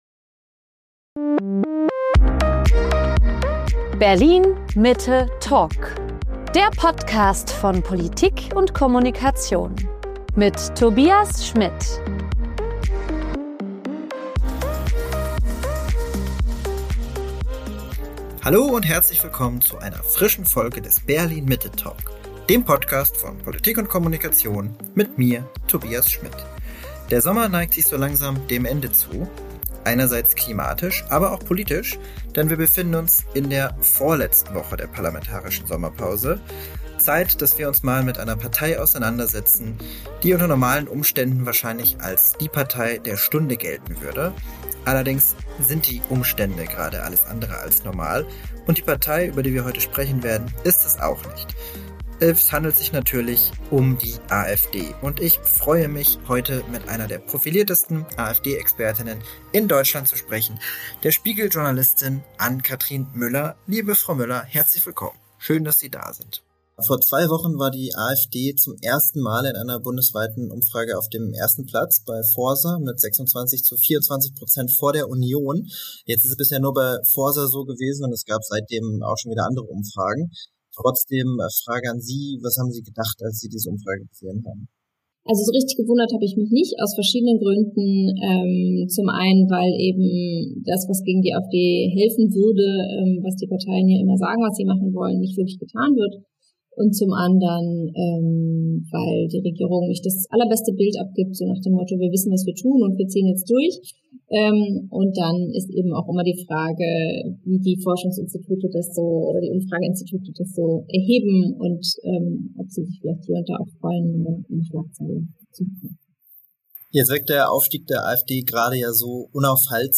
Mit ihr sprach ich darüber, wie die AfD zur Zeit um den richtigen Weg ringt, in Regierungsverantwortung zu kommen, und welche strategischen Lager dabei aufeinander treffen.